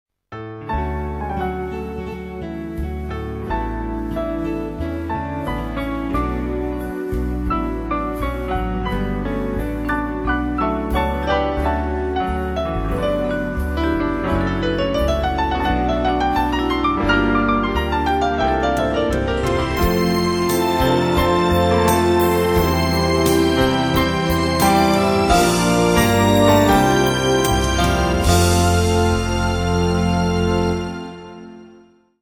arrang. jazz